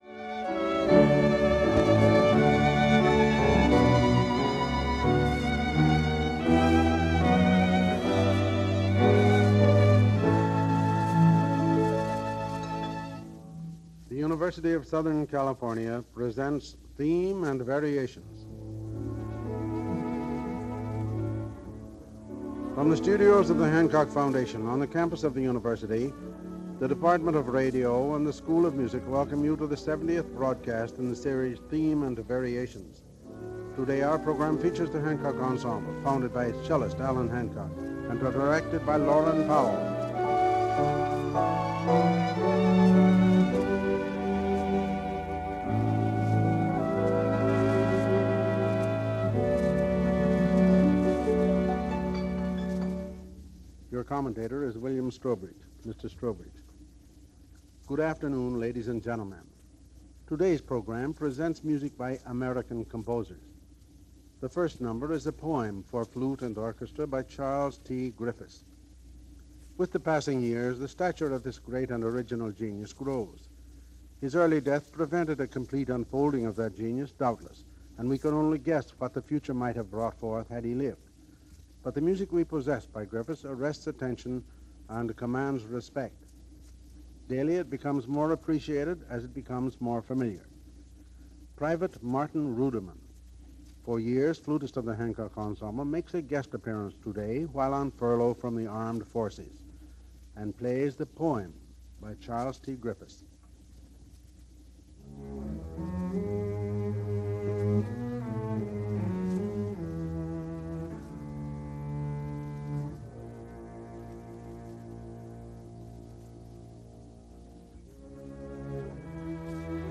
flute.